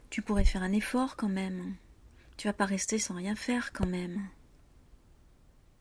On peut aussi exprimer une critique, avec un ton agacé: